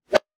weapon_bullet_flyby_10.wav